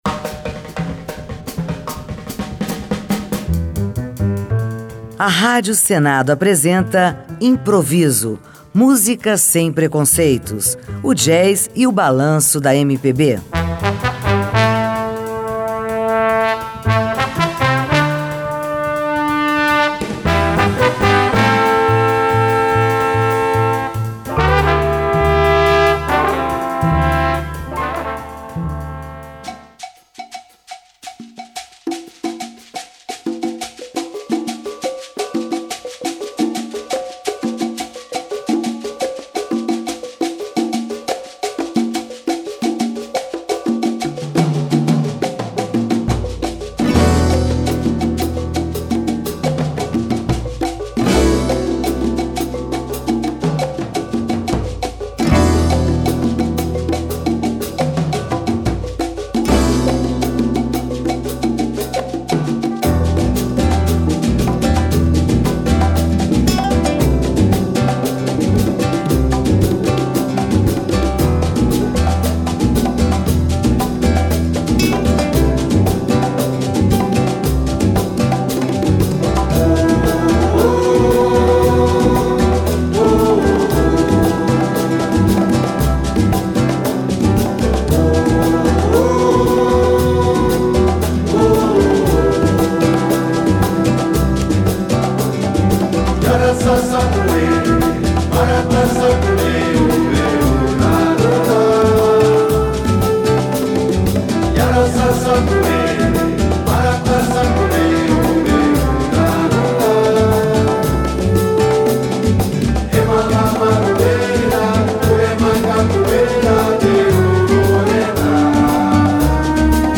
samba jazz